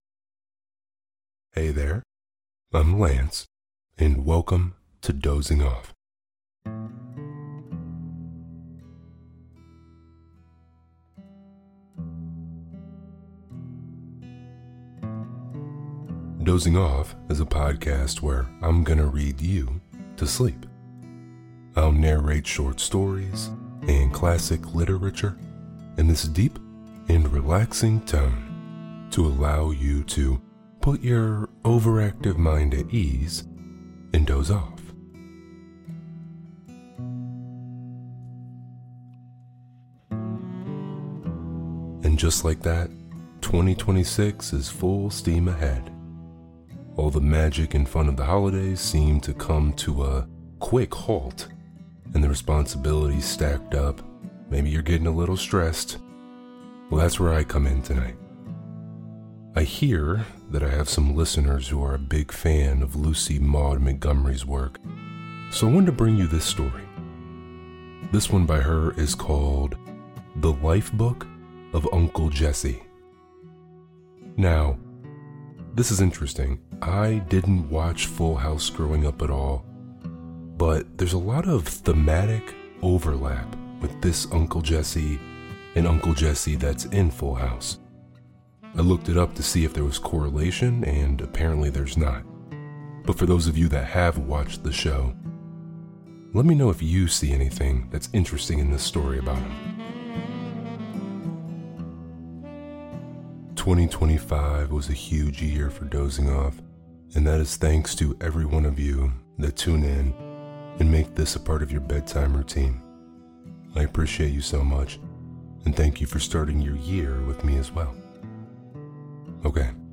Dozing Off | Deep Voice ASMR Bedtime Stories Podcast - The Life-Book of Uncle Jesse | Free Listening on Podbean App